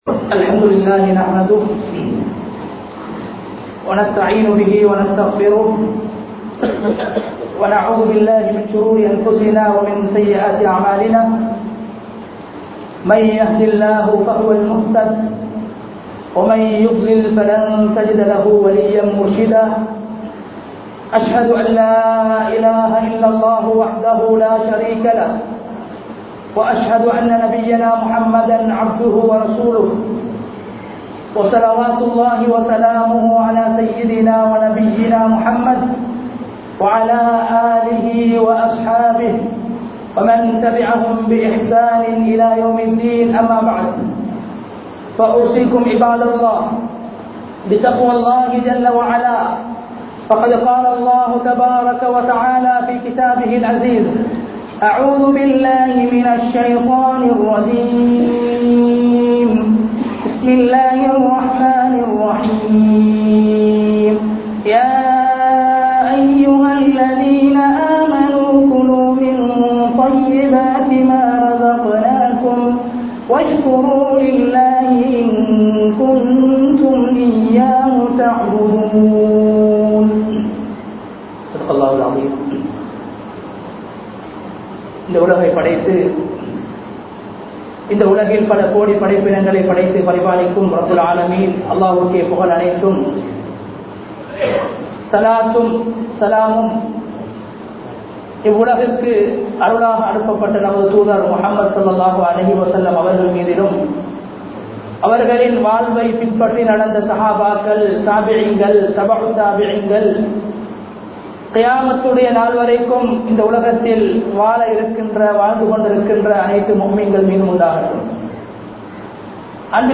போதைவஸ்தின் விளைவுகள் | Audio Bayans | All Ceylon Muslim Youth Community | Addalaichenai
Minuwangoda, kopiwatta Jumua Masjidh 2020-09-25 Tamil Download